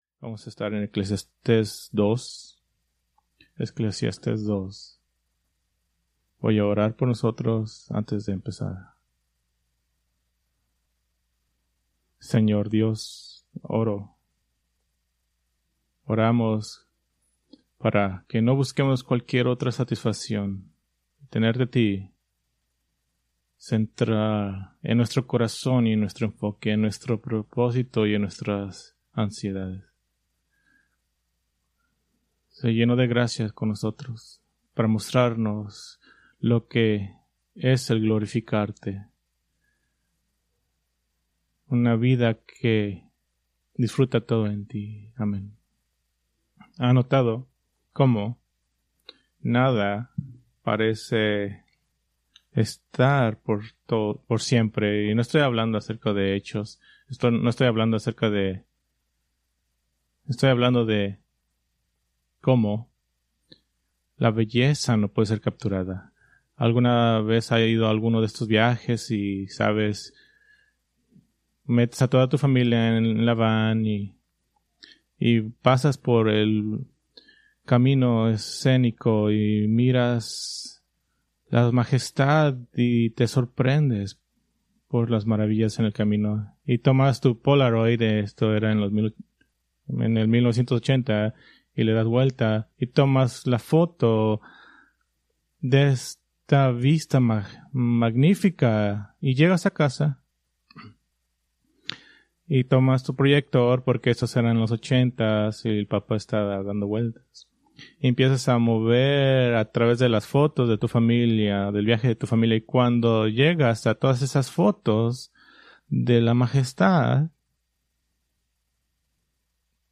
Preached July 13, 2025 from Eclesiastés 2